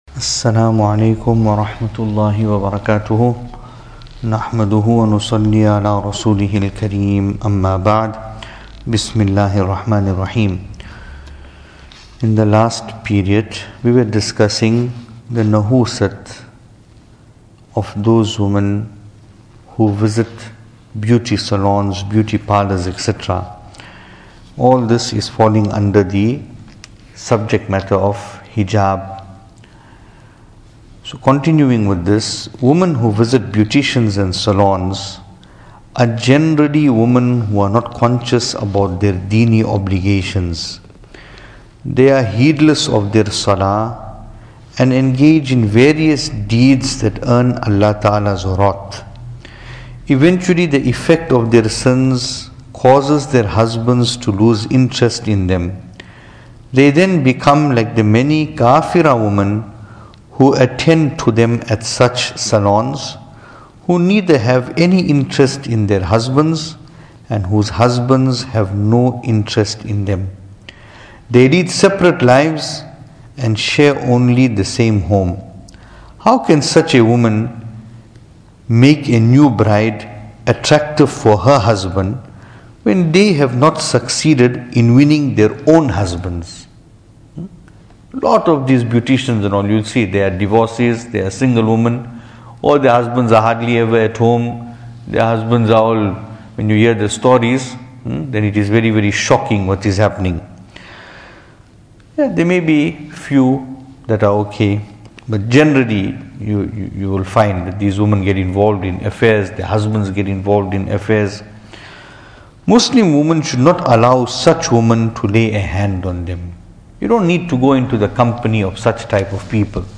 Venue: Pietermaritzburg | Series: Tohfa-e-Dulhan